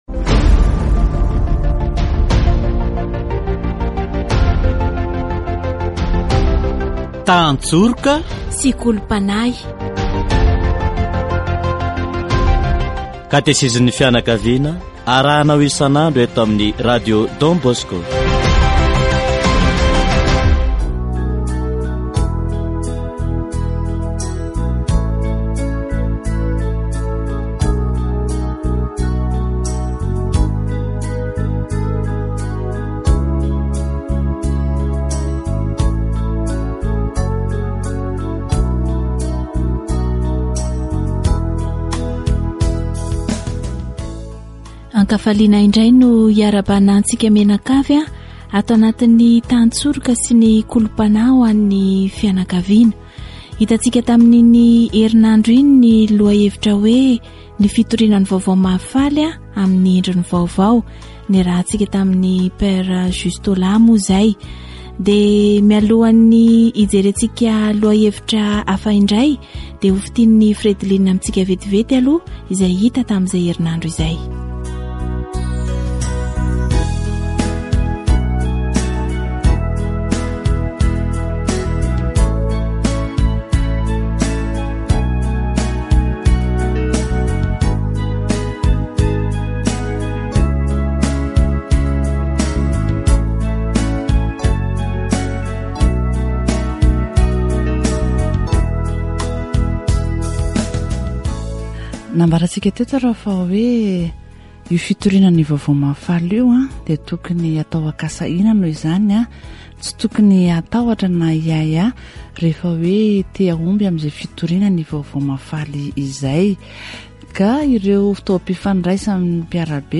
Catéchèse sur l'amour de Dieu